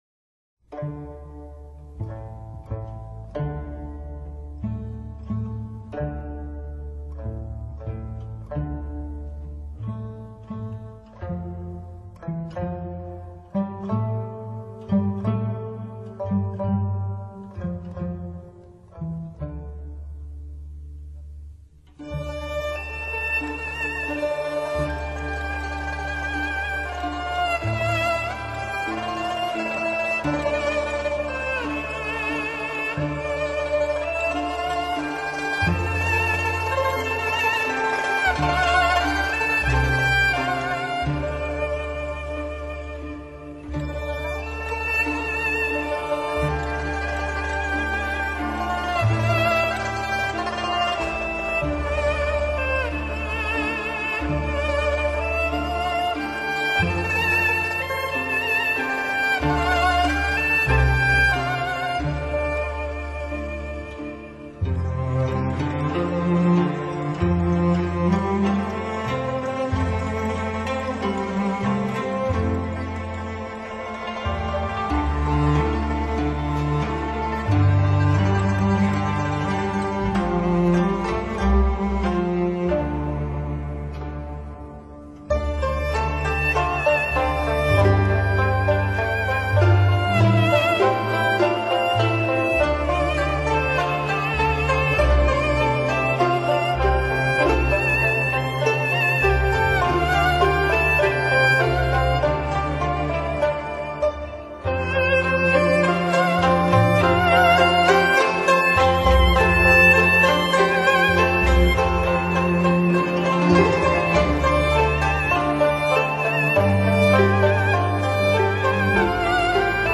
Genre: New Age
音乐风格：新世纪/凯尔特|新世纪|(New Age)